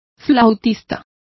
Complete with pronunciation of the translation of flautists.